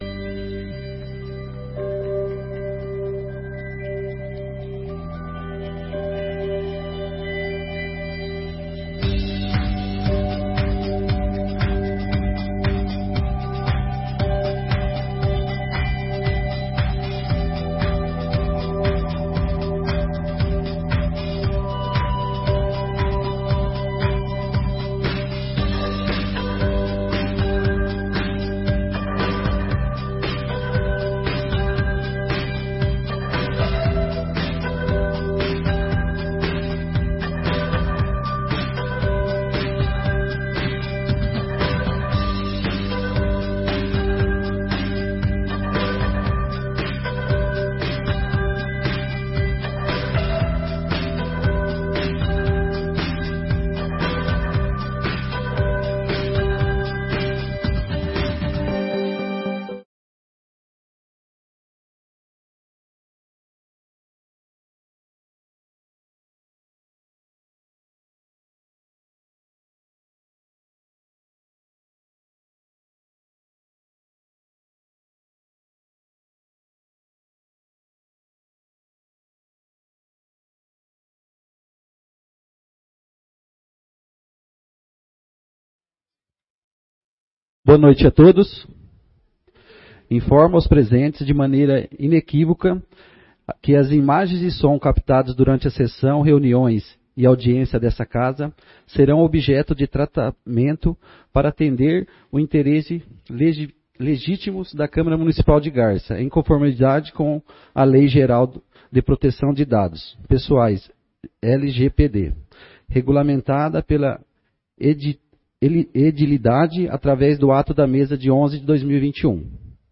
Audiência Pública: Discussão do Projeto da Lei Orçamentária para 2023
audiencia-publica-discussao-do-projeto-da-lei-orcamentaria-para-2023